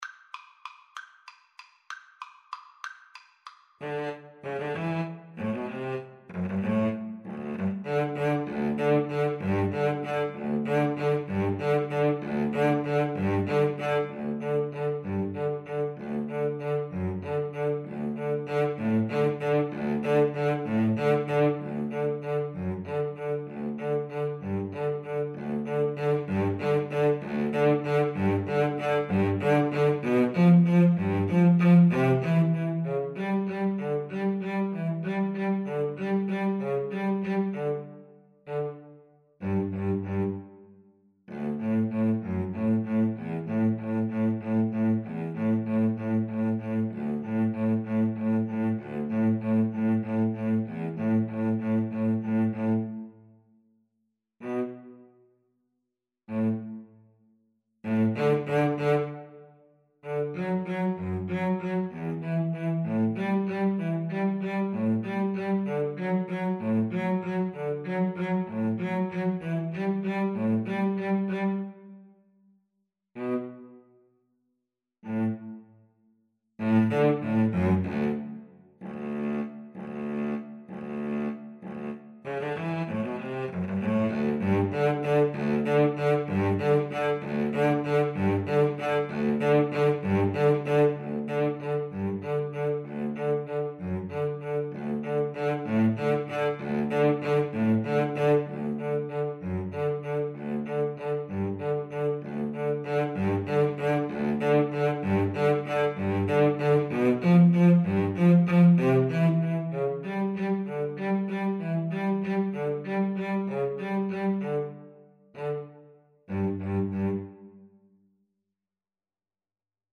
Free Sheet music for Cello Duet
Cello 1Cello 2
G major (Sounding Pitch) (View more G major Music for Cello Duet )
3/4 (View more 3/4 Music)
Tempo di Waltz (.=c.64)
Classical (View more Classical Cello Duet Music)